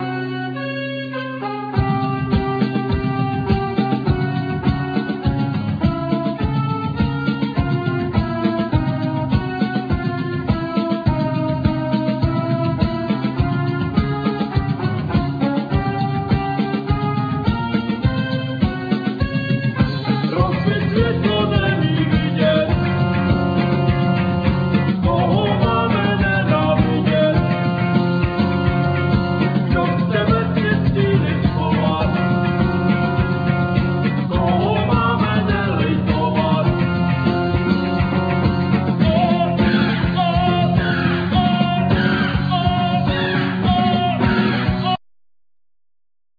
Vocal
Saxophone
Guitar
Bass
Drums
Violin
Piano